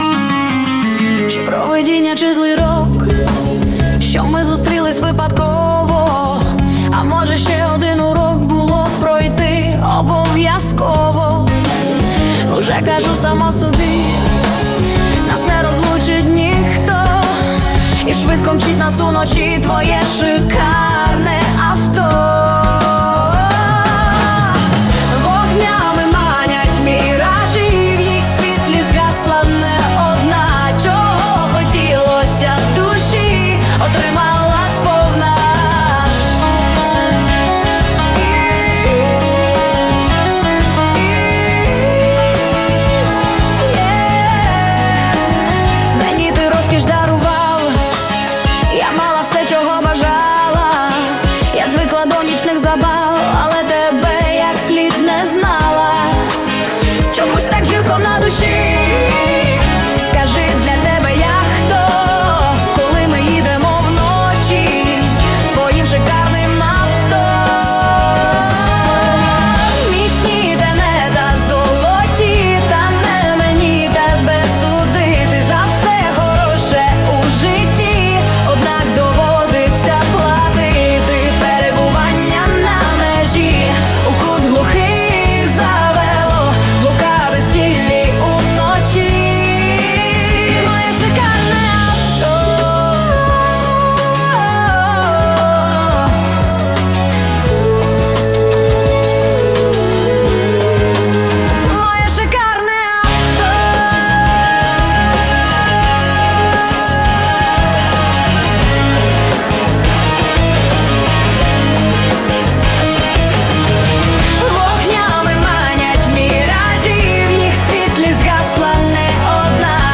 Стиль: поп, рок